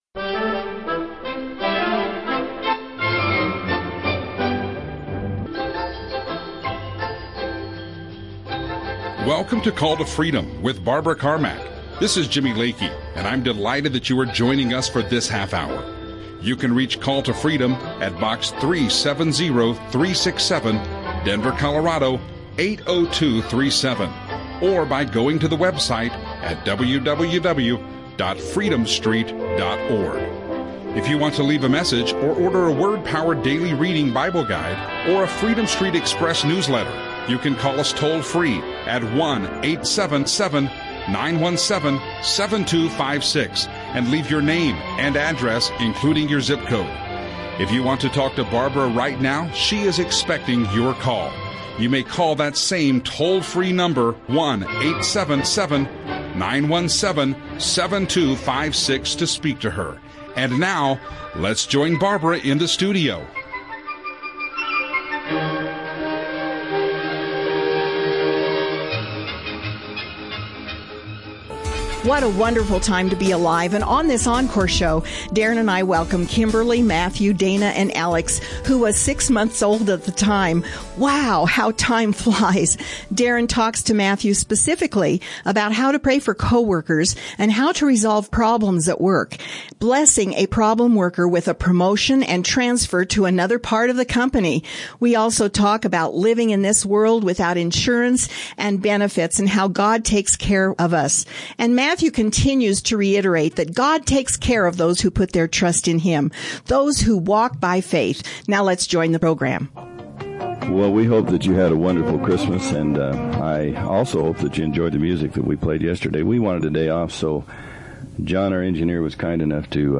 We all in unison proclaim that our LORD JESUS meets every need we have, whether it's financial, health issues or relational, He is there for you.
in Studio
Christian radio